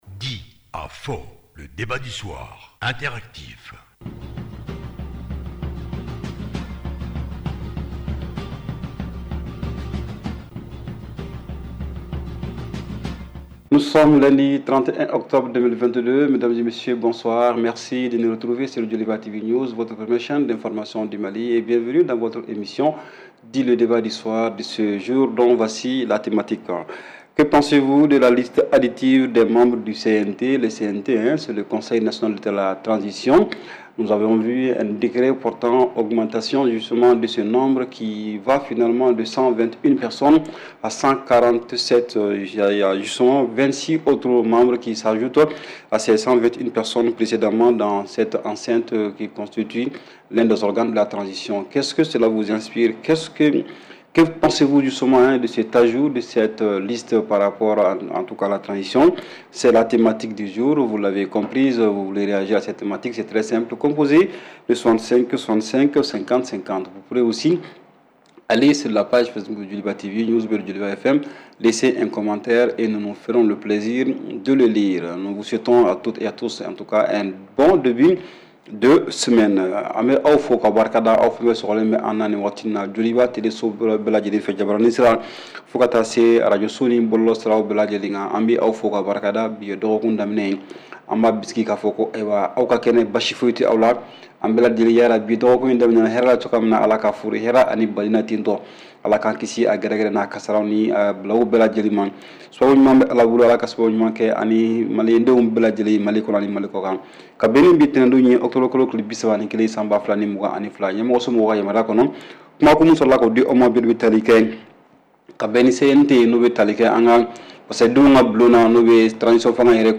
REPLAY 31/10 – « DIS ! » Le Débat Interactif du Soir